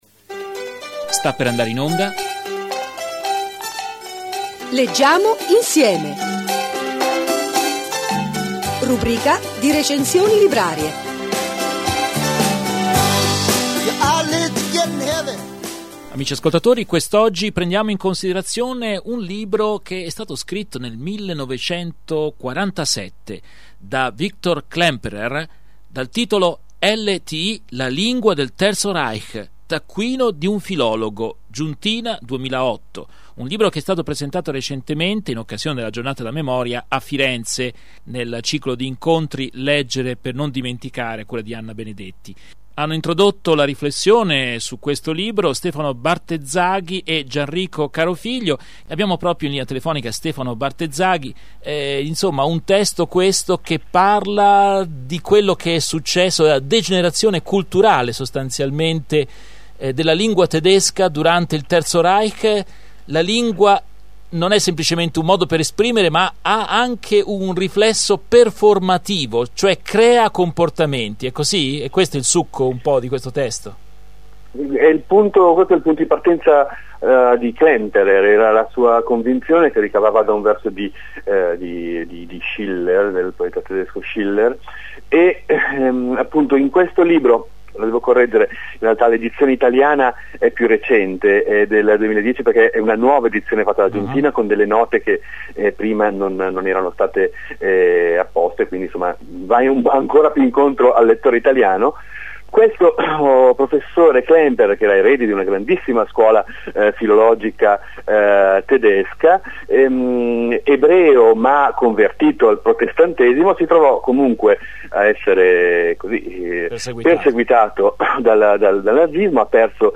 Radio Podcast
In questo numero di “Leggiamo insieme” parliamo con Stefano Bartezzaghi di un’opera di Victor Klemperer: “LTI La lingua del Terzo Reich.